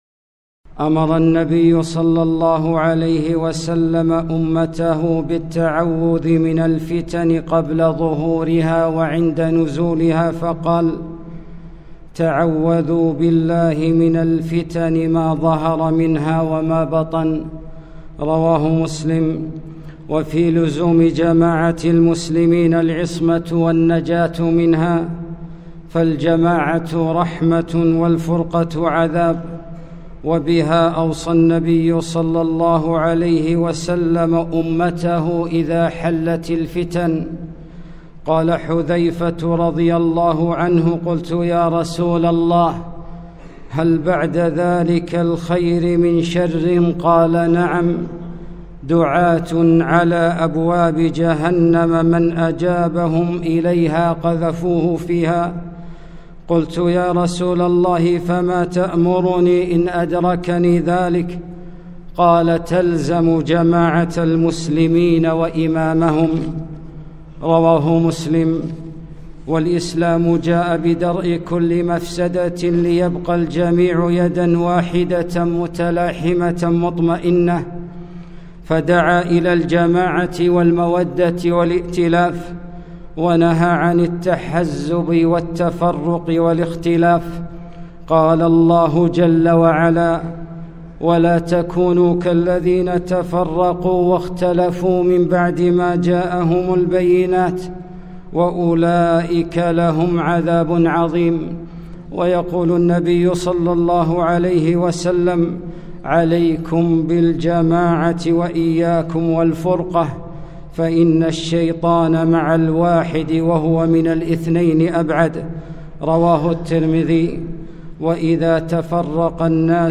خطبة - طائفية بغيضة وتحزبات مقيتة